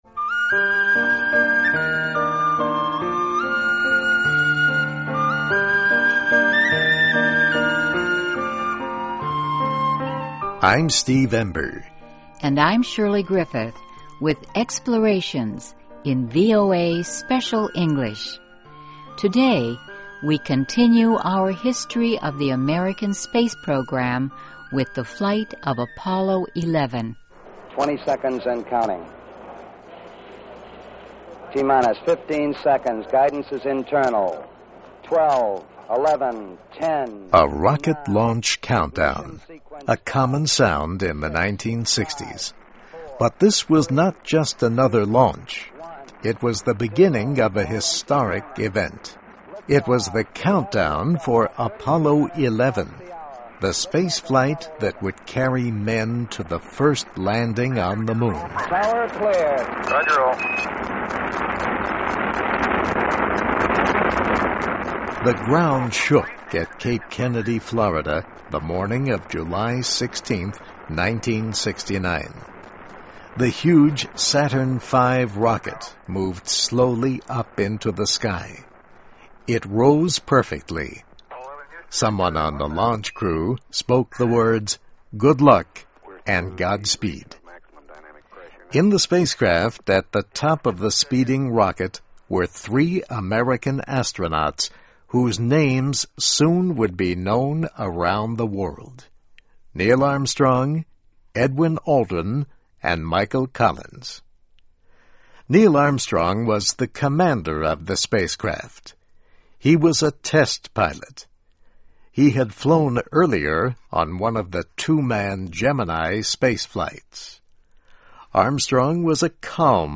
Listen and Read Along - Text with Audio - For ESL Students - For Learning English